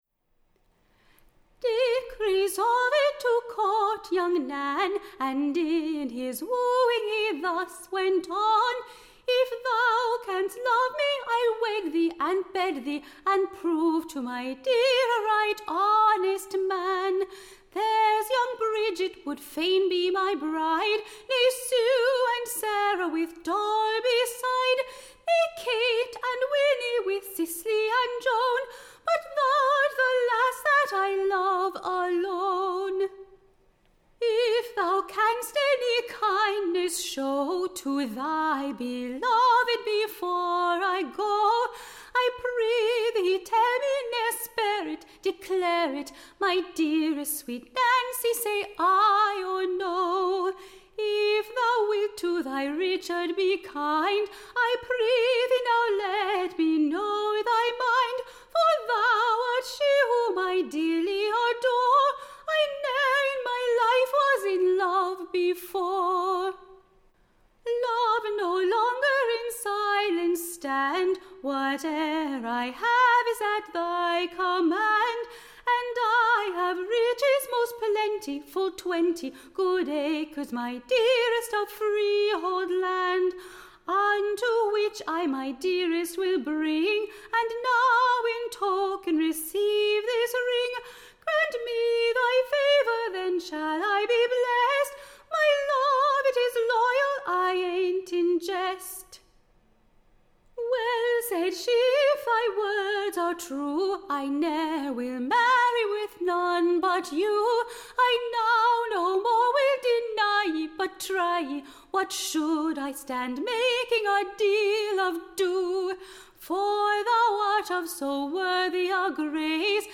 Recording Information Ballad Title DICKS LOYALTY / To his True Love NANCY: / OR, / A Famous Wedding: With an account of all the pleasant Passages that attended that Day.